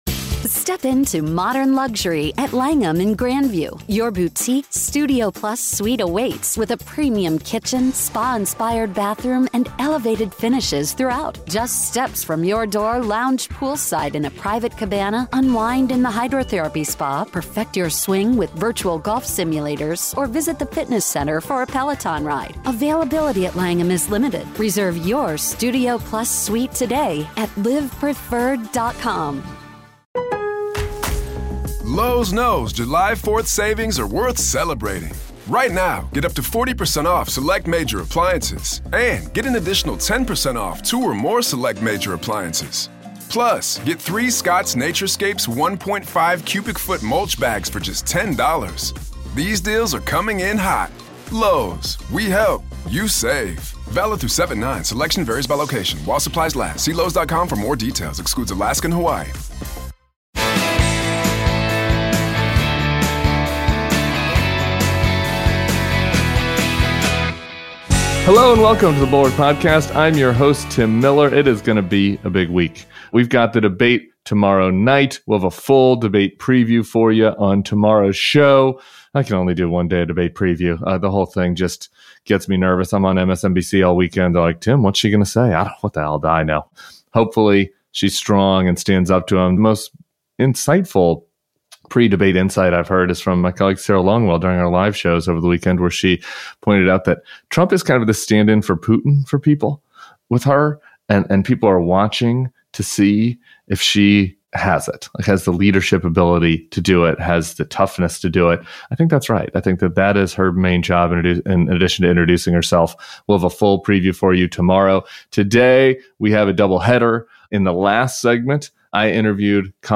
Tim Miller spoke with Rep. Colin Allred at TribFest in Austin, and Bill Kristol joined for a political news roundup.